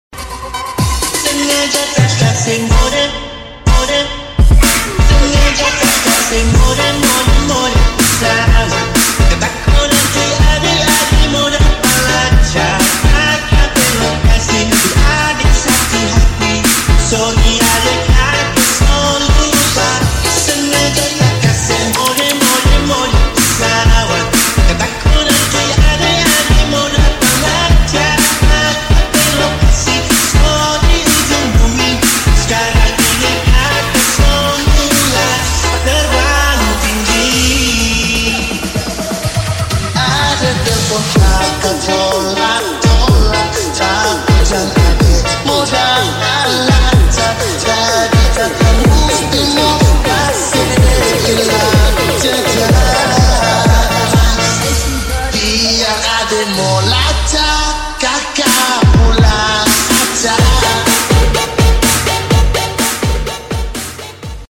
Granmax Pickup instal Side Exit